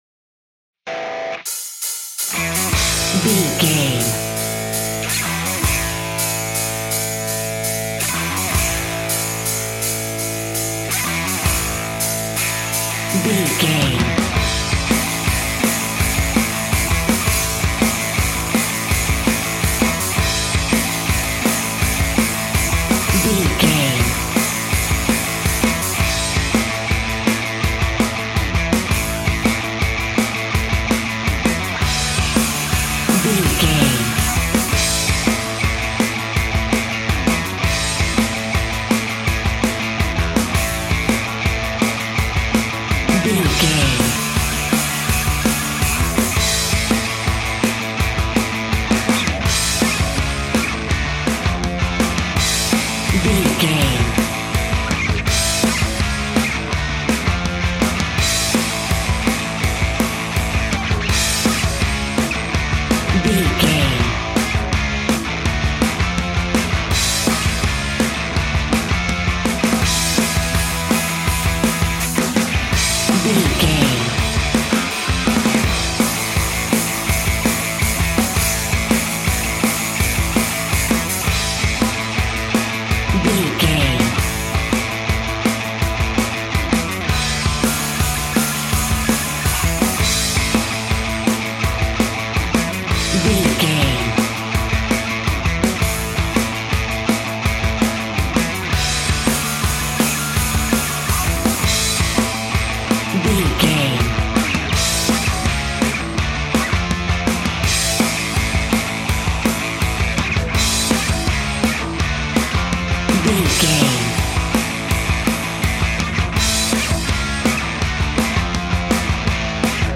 Ionian/Major
energetic
uplifting
instrumentals
indie pop rock music
upbeat
groovy
guitars
bass
drums
piano
organ